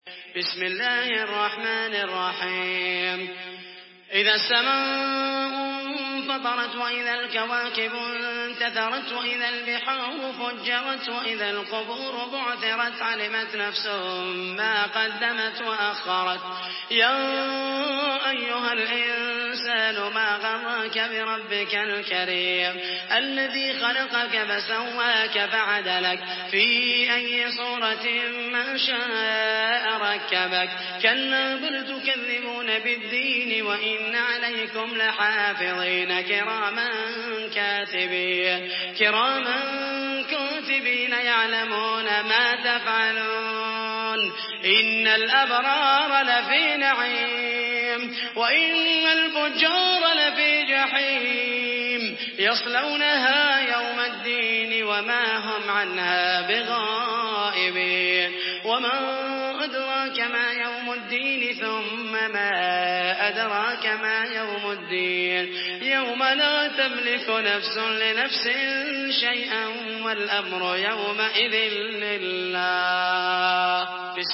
Surah الانفطار MP3 by محمد المحيسني in حفص عن عاصم narration.
مرتل حفص عن عاصم